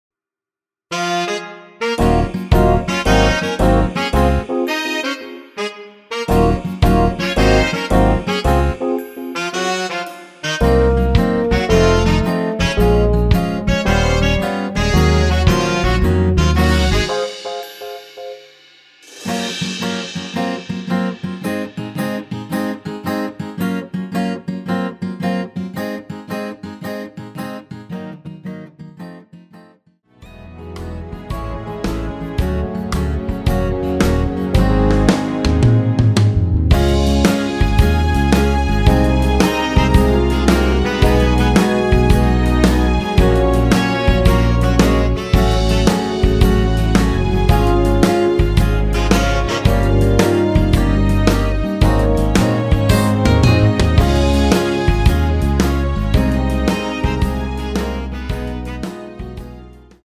내린 MR 입니다.
Db
앞부분30초, 뒷부분30초씩 편집해서 올려 드리고 있습니다.